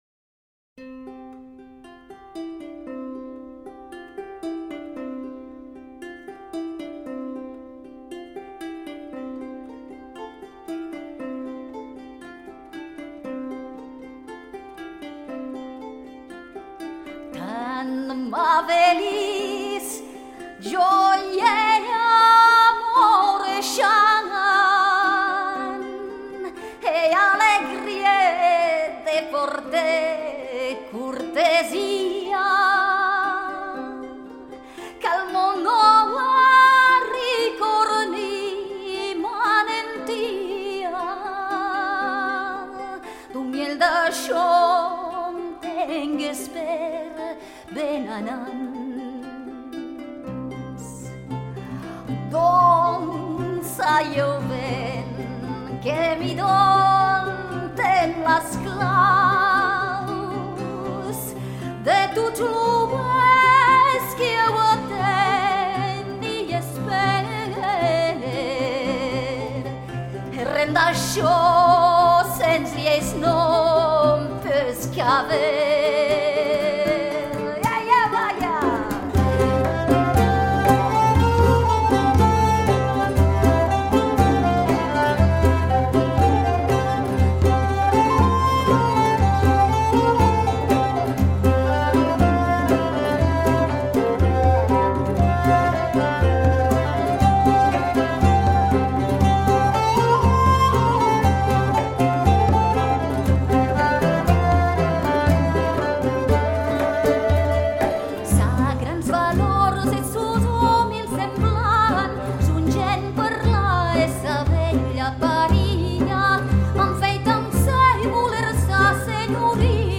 Canzone
Group: Song